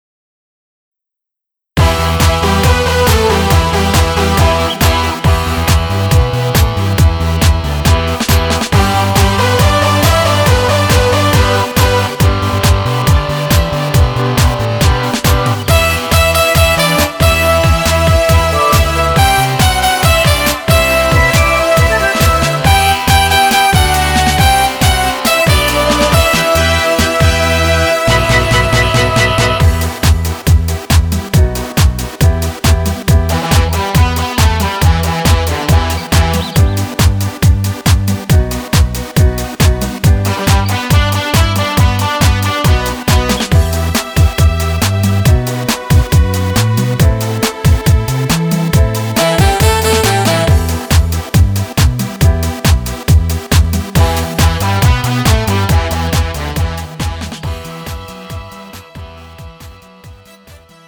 음정 여자-1키
장르 가요 구분 Pro MR